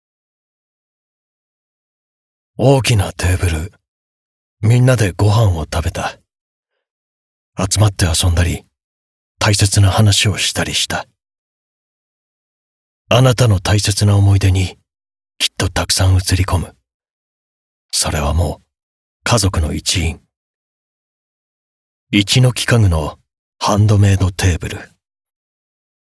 ボイスサンプル
• ナレーション：ナチュラル語り、生命保険、暮らし密接等